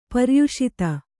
♪ paryuṣita